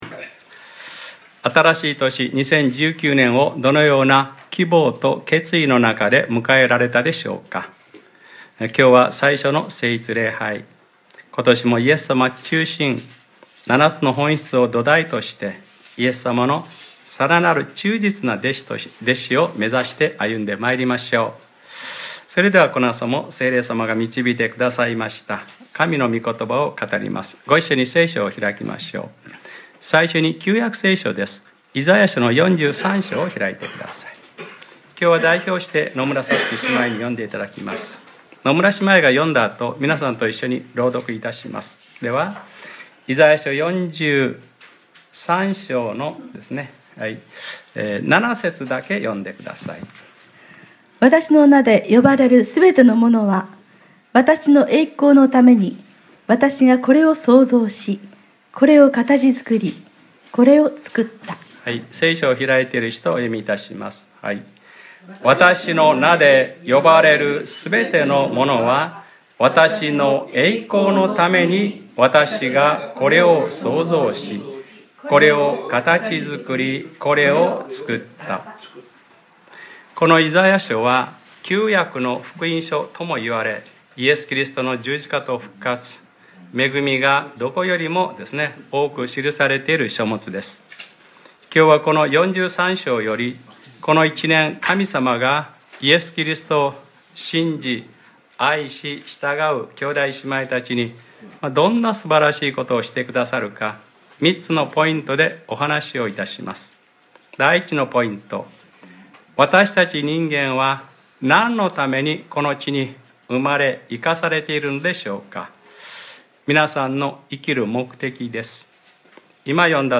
2019年01月06日（日）礼拝説教『見よ。わたしは新しいことをする。』